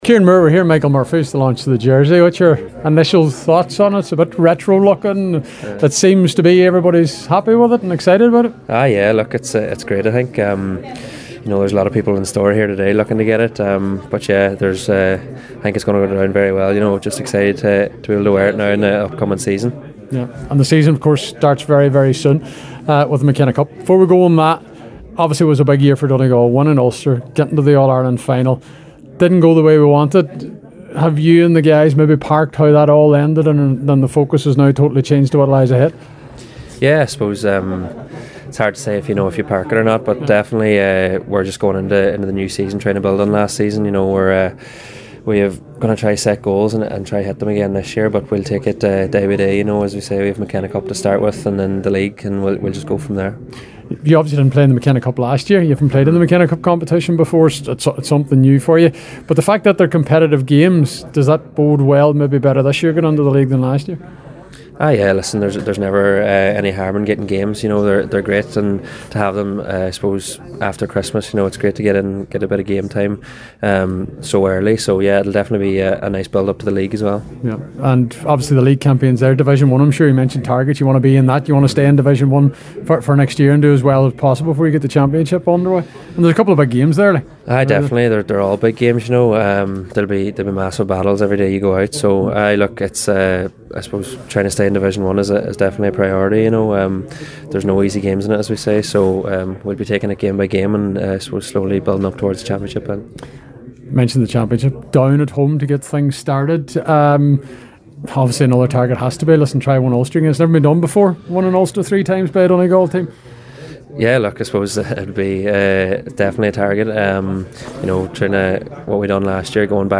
at the launch of the new Donegal Jersey in Michael Murphy Sports